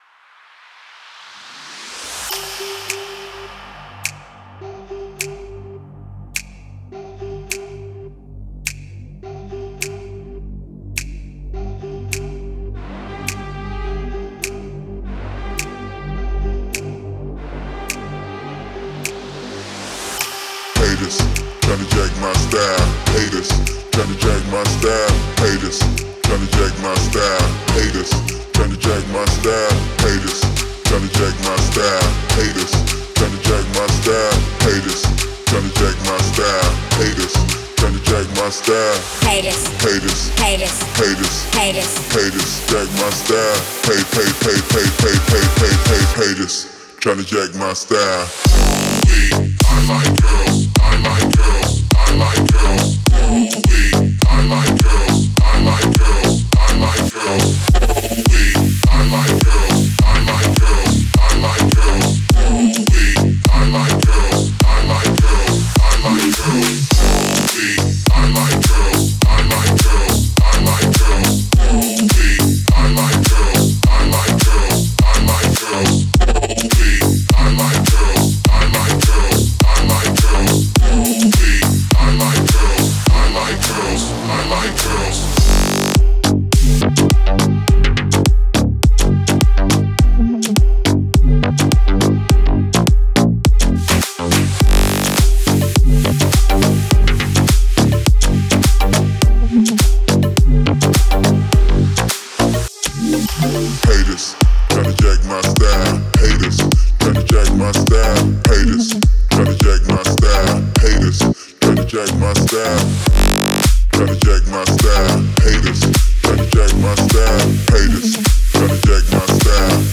это энергичная композиция в жанре хип-хоп
Звучание сочетает мощные биты с запоминающимися мелодиями